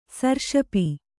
♪ sarṣapi